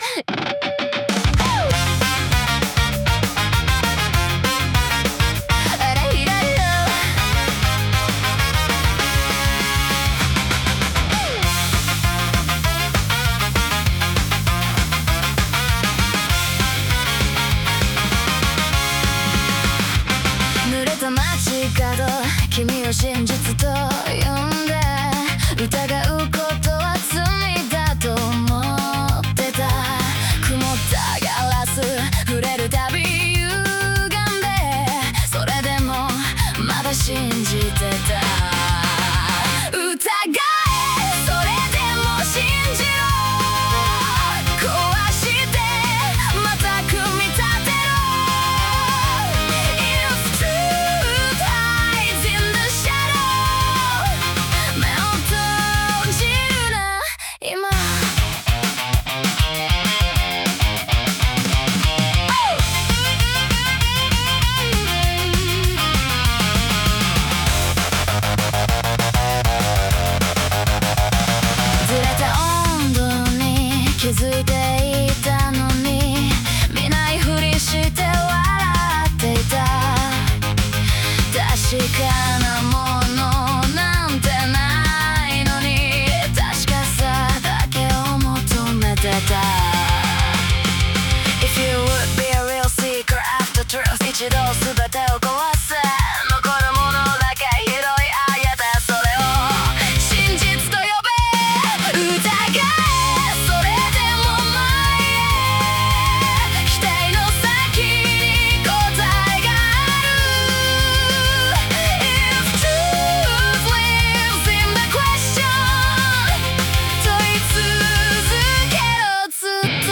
歌ものフリー素材 bgm音楽 無料ダウンロード 商用・非商用ともに登録不要で安心してご利用いただけます。
女性ボーカル